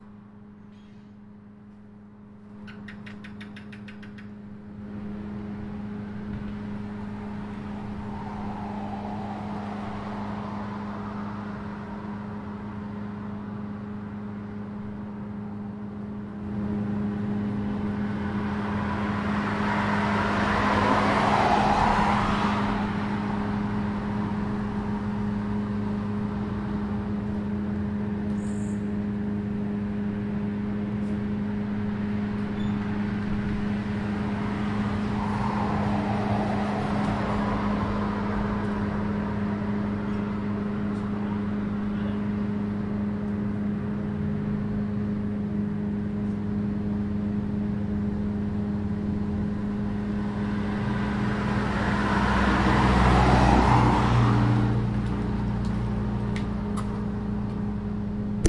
Shenzhen street noise
描述：2015 Street noise in Chinanear Shenzhen University
标签： Shenzhen streetnoise China ambience cars traffic street ambient car people fieldrecording generalnoise noise city town
声道立体声